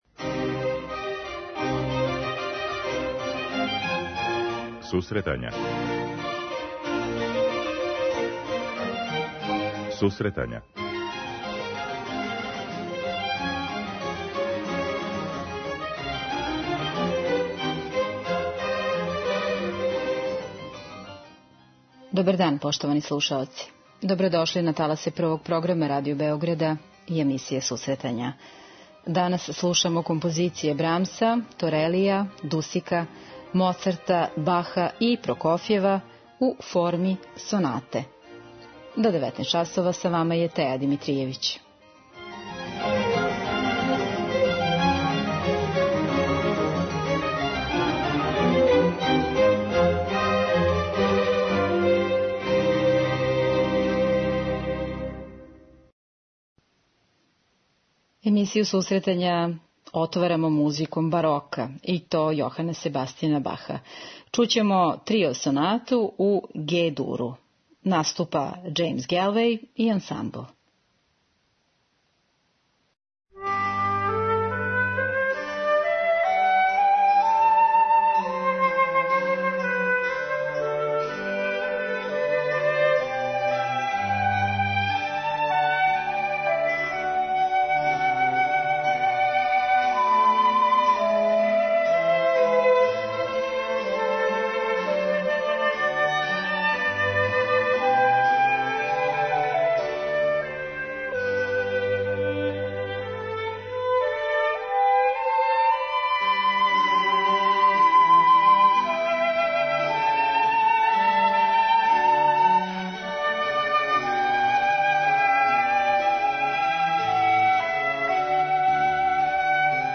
Данас слушамо композиције сонатног облика од периода барока до 20.века